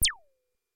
标签： MIDI网速度116 B4 MIDI音符-71 挡泥板-色度北极星 合成器 单票据 多重采样
声道立体声